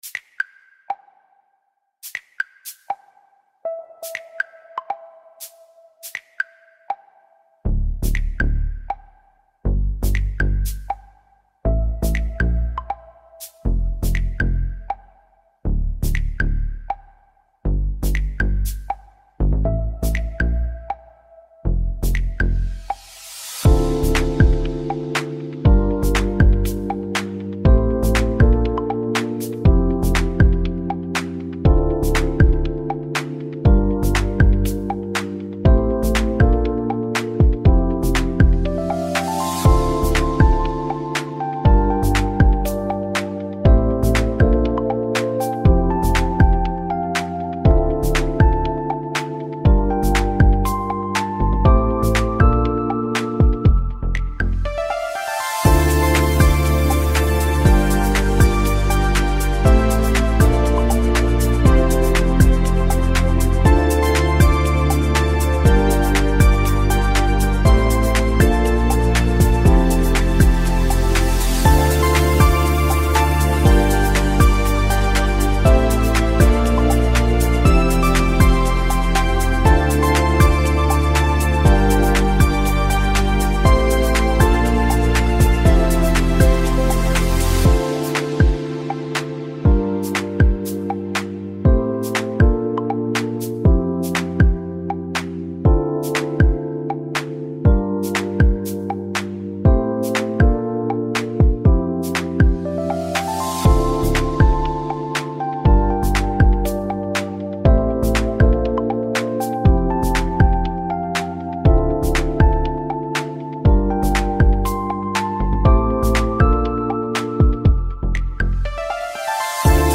ハイテクなデジタルテクスチャ、洗練されたネオンの雰囲気、クリーンなエレクトロニックビート、プロフェッショナルで先進的な、現代的で未来的なシンセウェーブ、110bpm、インストゥルメンタル
複雑な技術をわかりやすく解説するイメージに合わせた、スマートで都会的なテック・ビート。